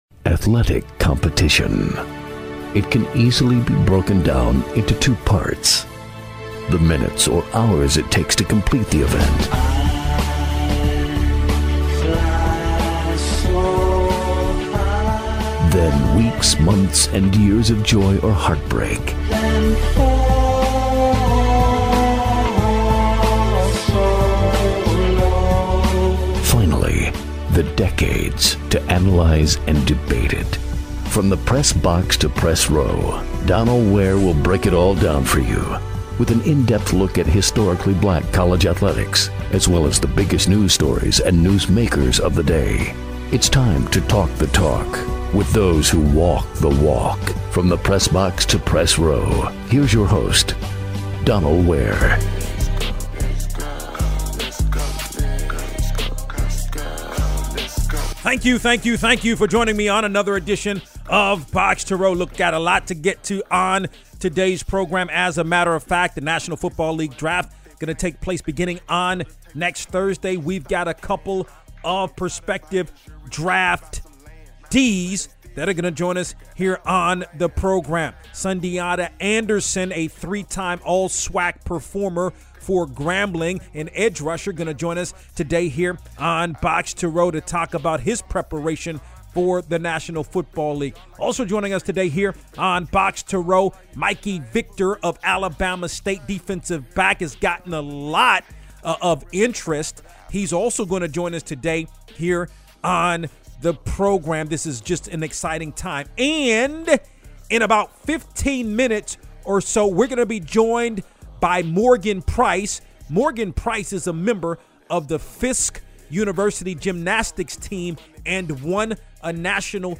interview
radio program